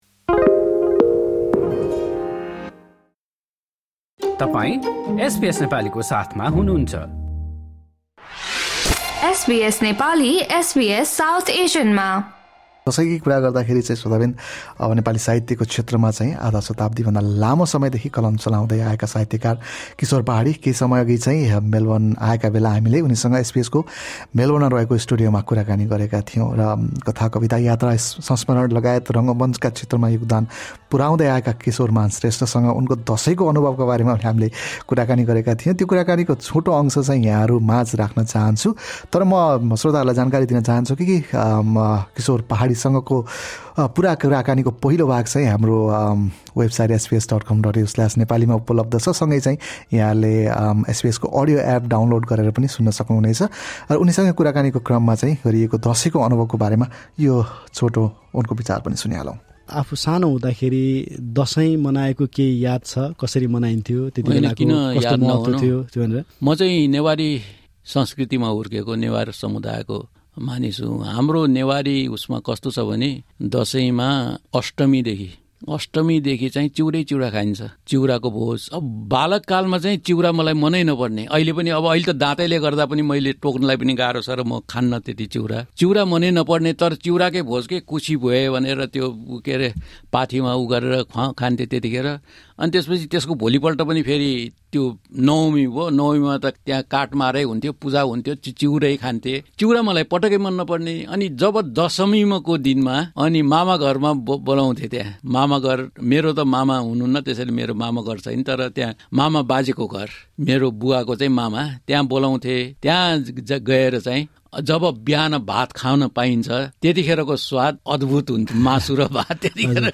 मेलबर्न आएको बेला हामीले उनीसँग एसबीएसको मेलबर्न स्टुडियोमा कुराकानी गरेका थियौँ।